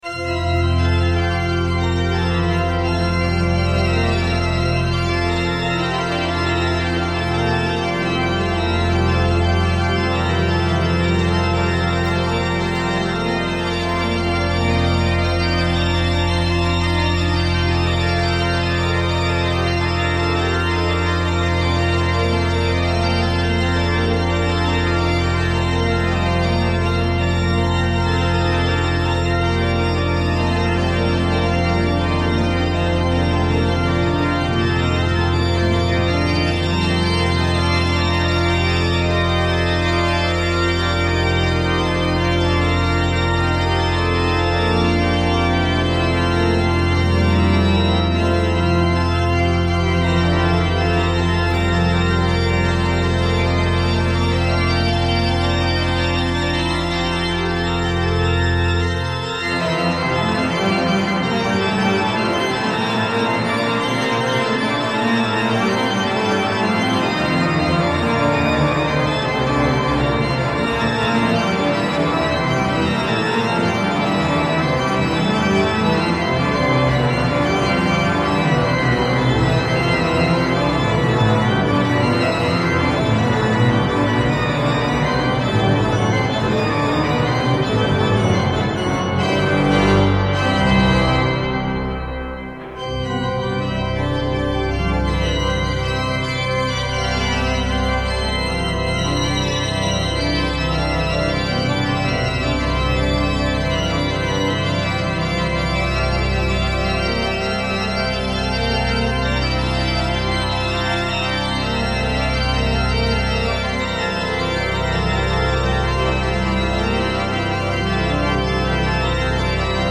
El organista inglés
al Órgano del Sol Mayor de Marbella.